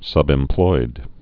(sŭbĕm-ploid)